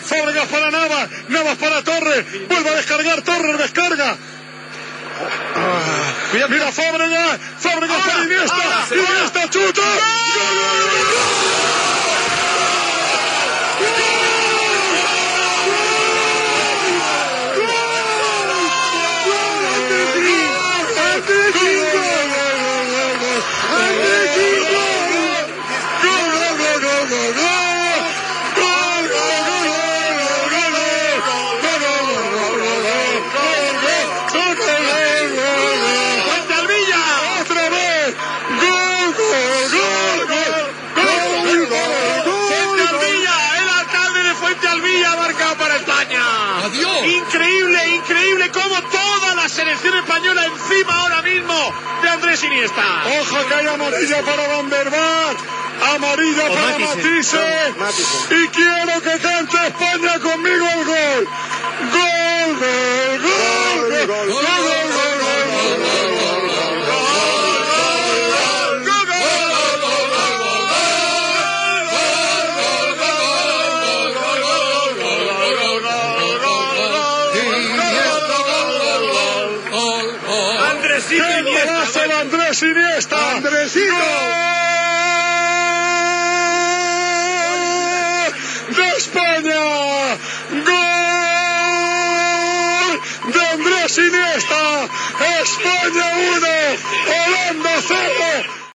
Transmissió del partit de la final de la Copa del Món de Futbol masculí 2010, des de Sud-àfrica.
Esportiu